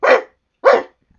dogs_0007.wav